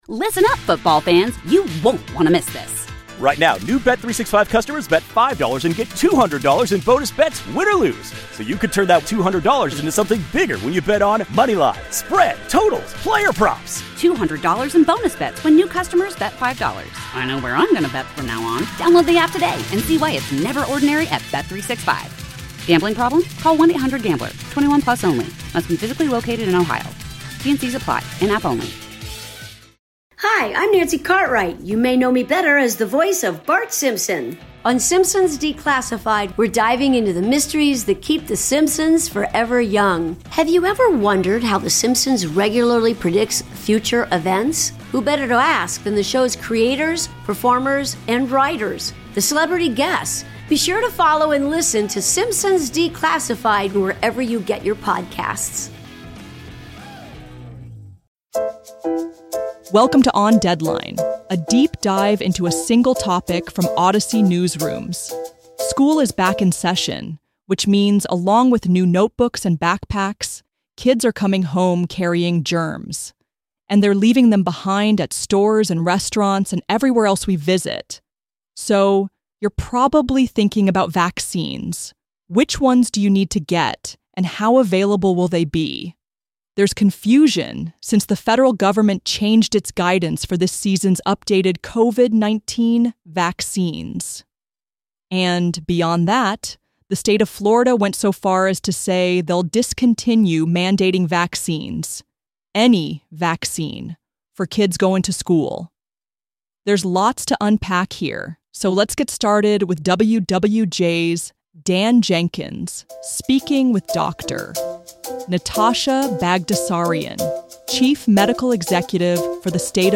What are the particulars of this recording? each episode will focus on news audio retrieved from the KCBS Radio archives.